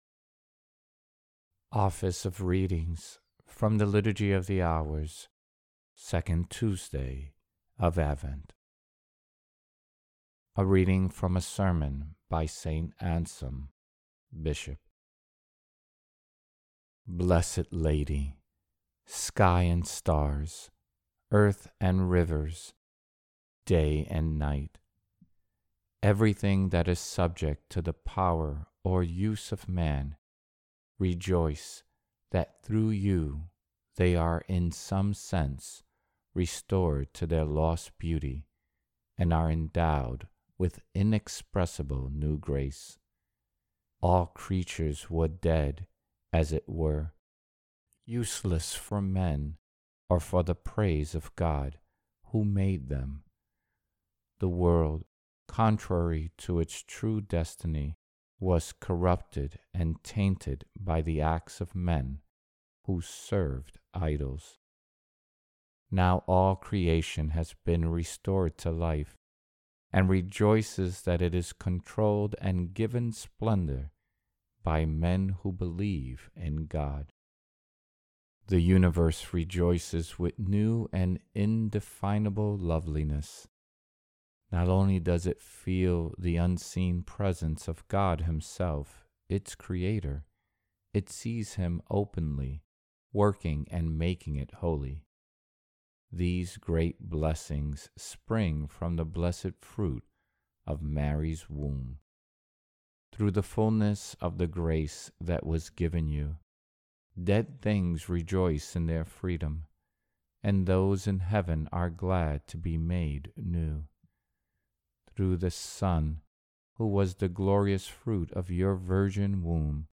Office of Readings – 2nd Tuesday of Advent